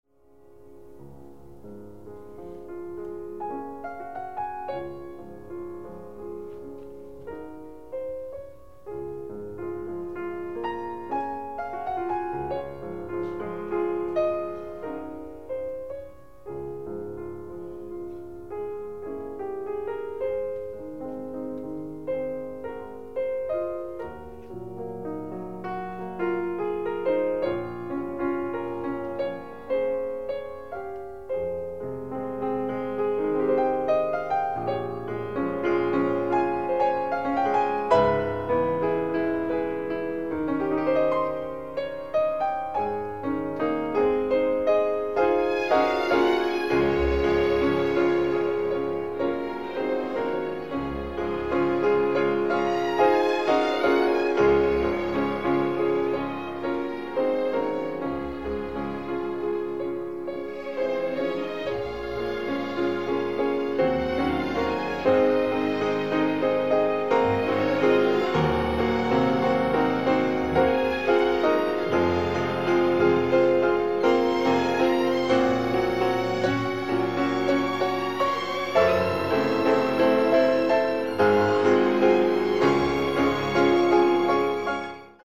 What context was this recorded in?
Additional Date(s)Recorded October 5, 1962 in the Ed Landreth Hall, Texas Christian University, Fort Worth, Texas Short audio samples from performance